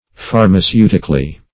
Phar`ma*ceu"tic*al*ly, adv.
pharmaceutically.mp3